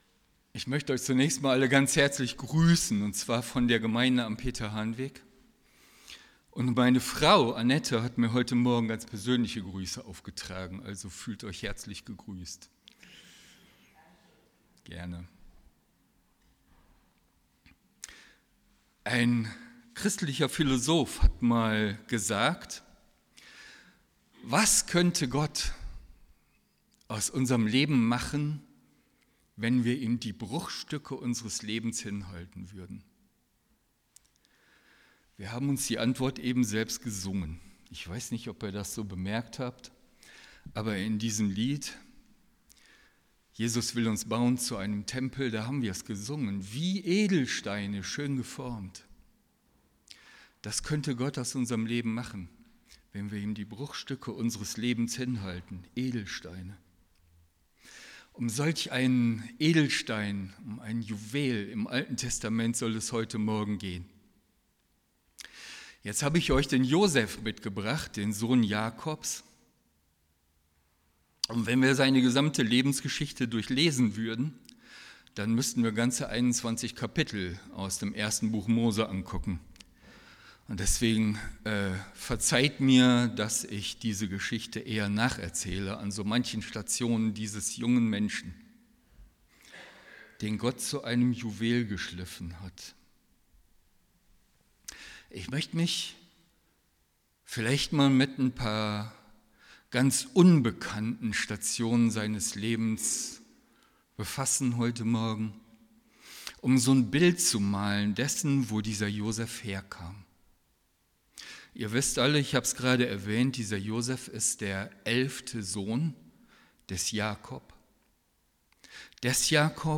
alle Predigten - Stadtmission Solingen